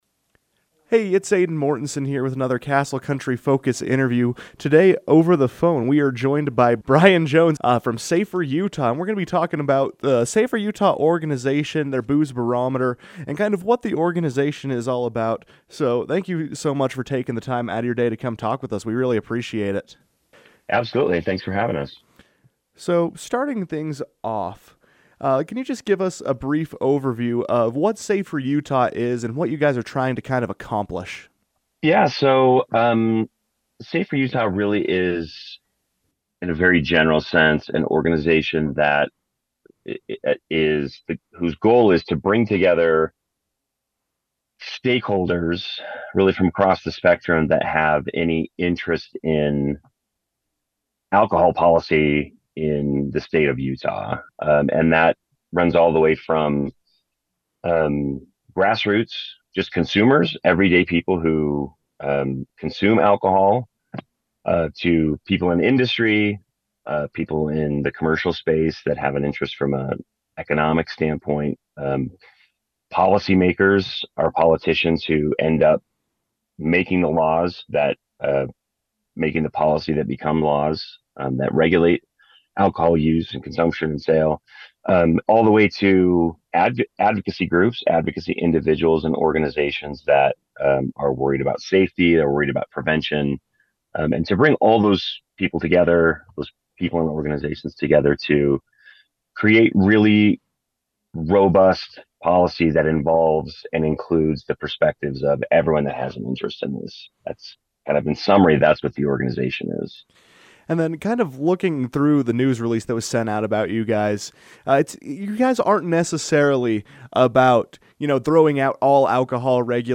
called into the KOAL Newsroom to discuss the organization’s goals and its ‘Booze Barometer” survey.